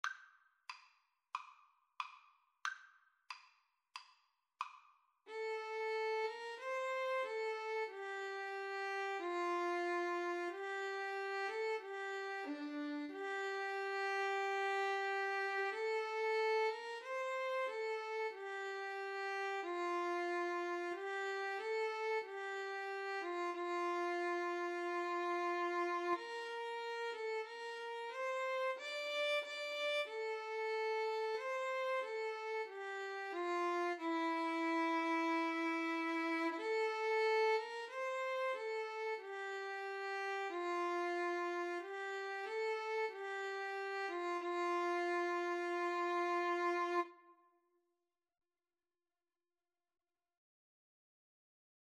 Clarinet-Violin Duet version
Andante = c. 92